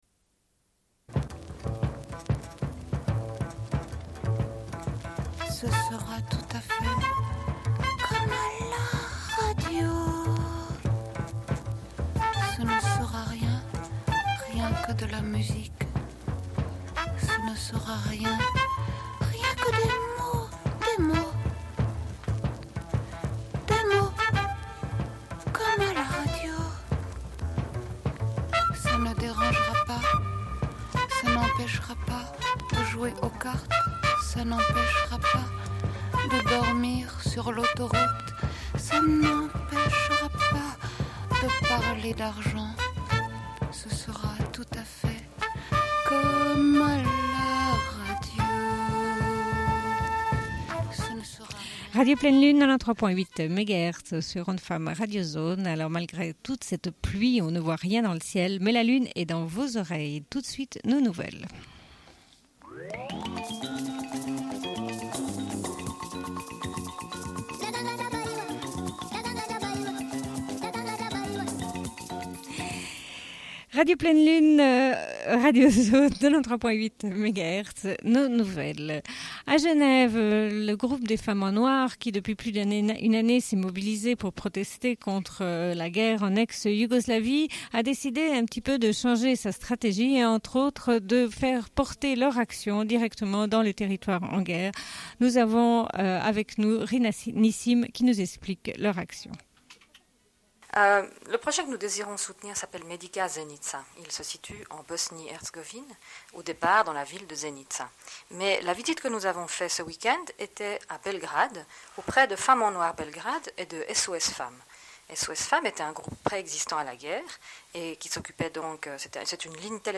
Bulletin d'information de Radio Pleine Lune du 13.10.1993 - Archives contestataires
Une cassette audio, face B28:45